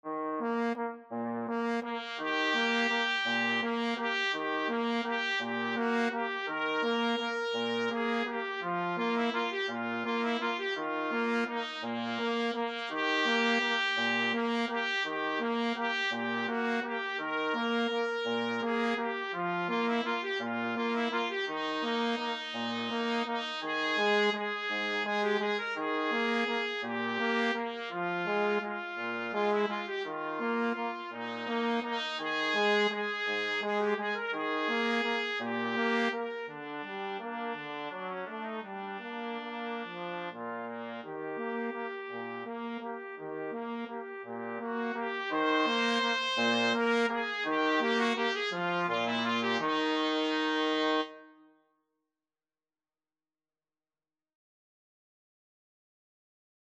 3/4 (View more 3/4 Music)
Tempo di valse =168
Classical (View more Classical Trumpet-Trombone Duet Music)